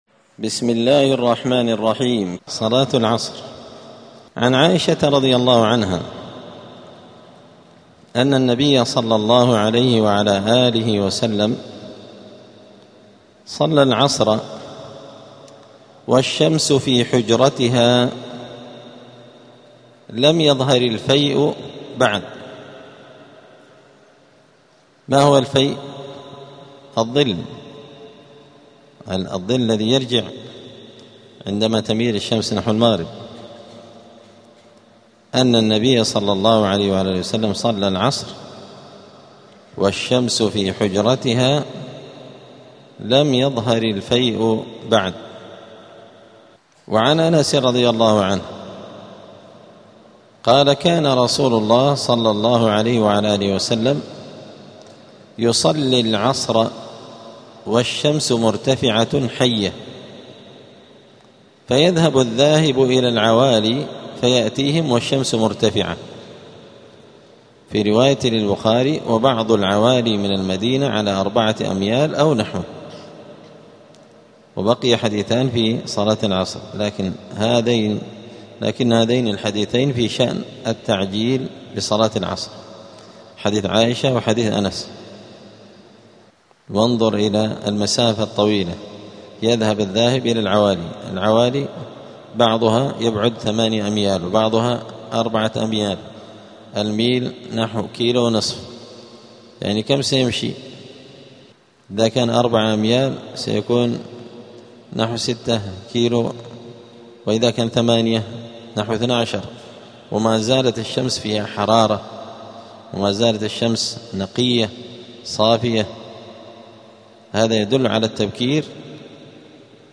دار الحديث السلفية بمسجد الفرقان قشن المهرة اليمن
*الدرس الرابع والثلاثون بعد المائة [134] {صلاة العصر}*